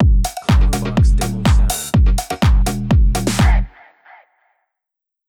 “Dusk Resonance” Clamor Sound Effect
Can also be used as a car sound and works as a Tesla LockChime sound for the Boombox.